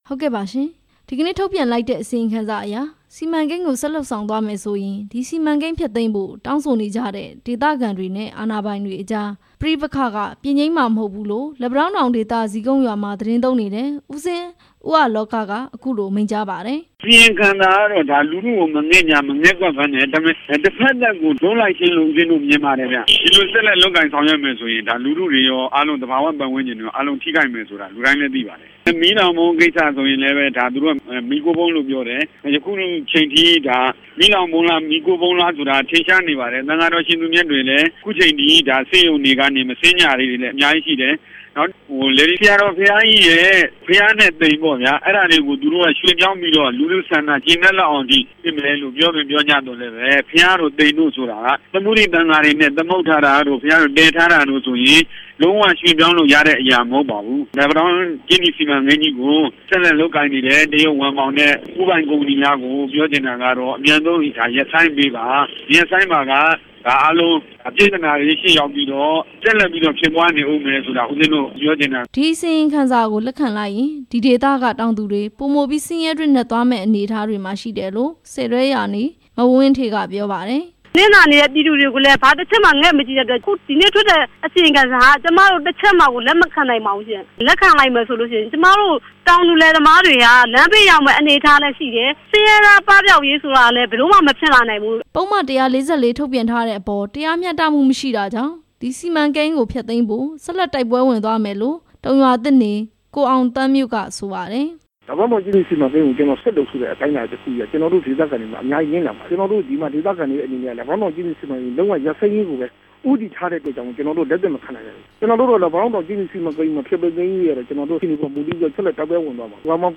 ဒေသခံတွေရဲ့ ပြောပြချက် နားထောင်ရန်